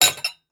mutfak ses efektleri:)